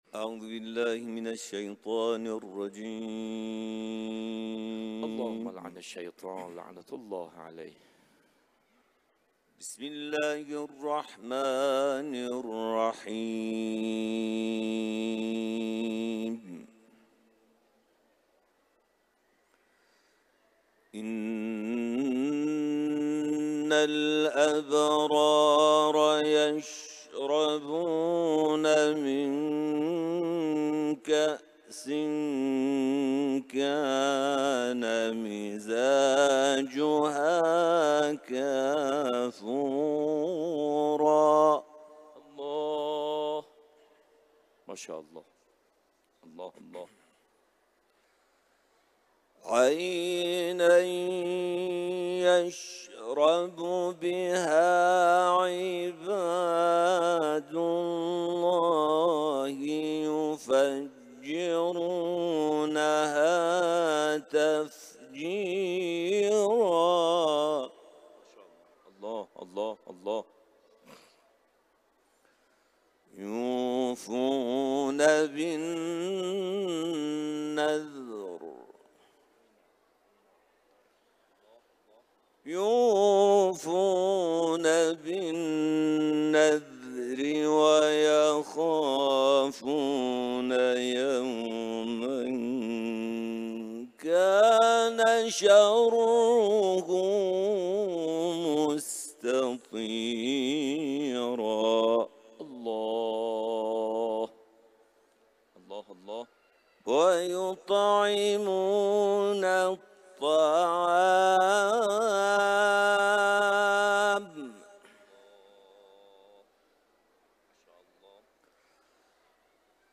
Etiketler: İranlı kâri ، kuran ، tilavet